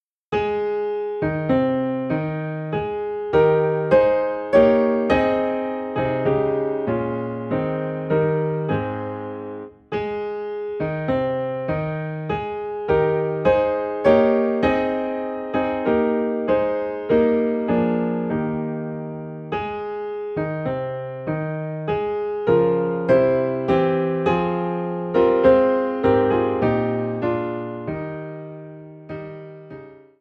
Hi Ab